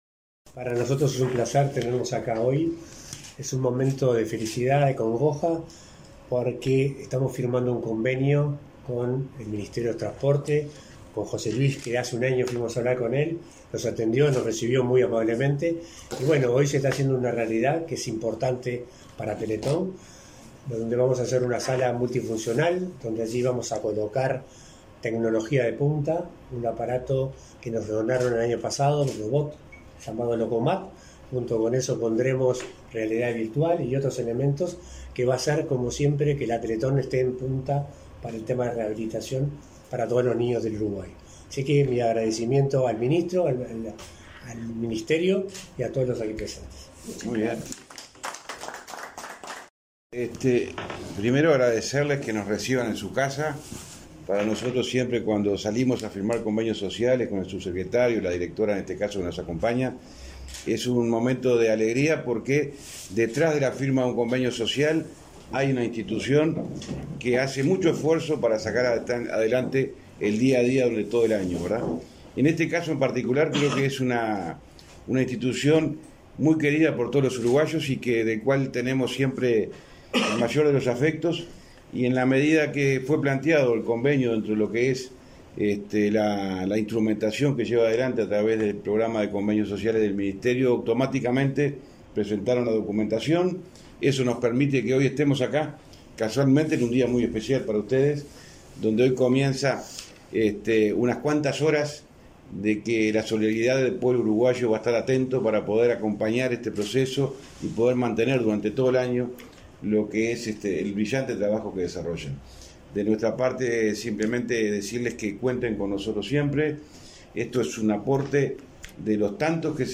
Acto de firma de convenio social del MTOP con Fundación Teletón